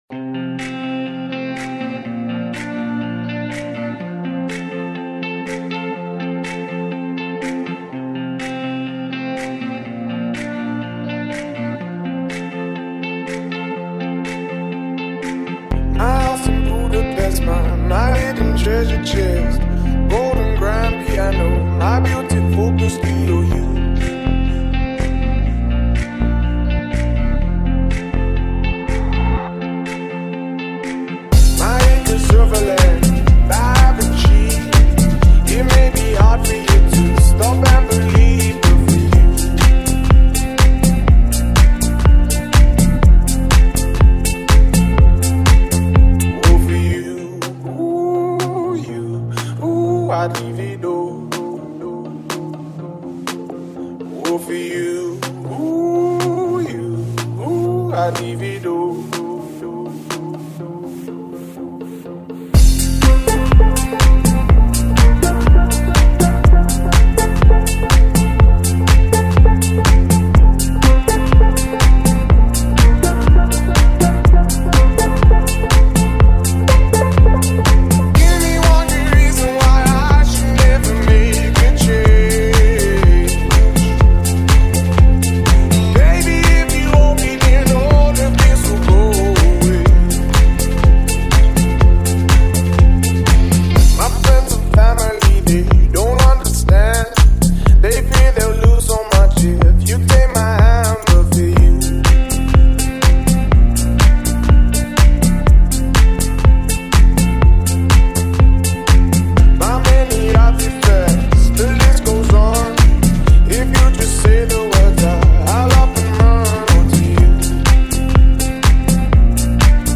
nice danceable remixed version
tropical injection of sounds
steady soulful sounds